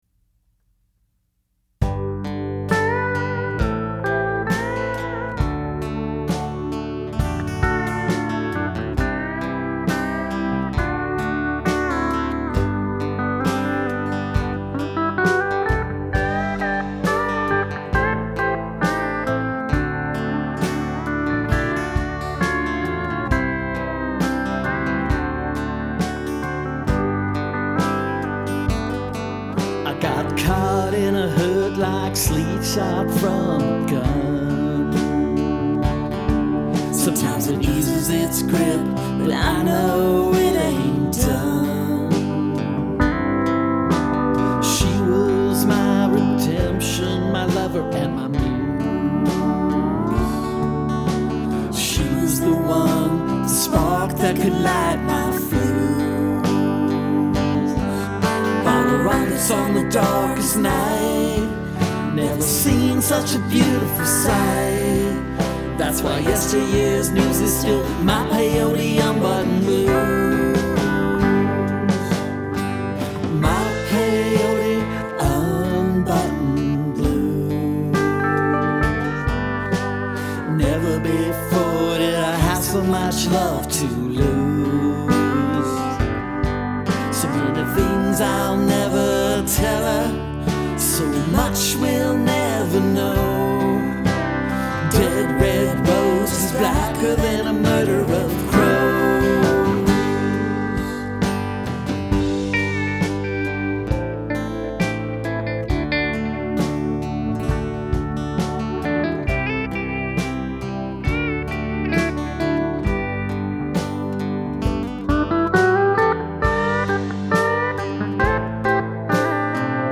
These are our demos — we recorded it all ourselves at home.